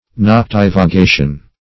Search Result for " noctivagation" : The Collaborative International Dictionary of English v.0.48: Noctivagation \Noc*tiv`a*ga"tion\, n. A roving or going about in the night.